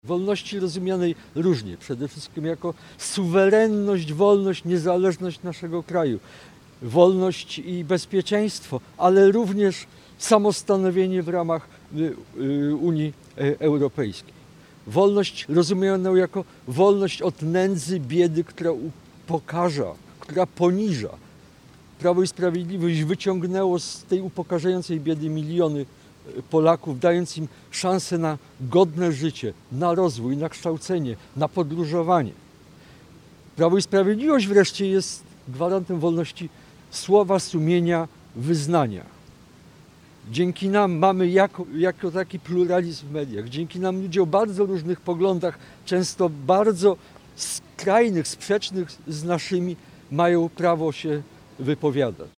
Na Placu Gołębim we Wrocławiu zgromadzili się senatorowie i posłowie, a także osoby ubiegające się o mandat do parlamentu.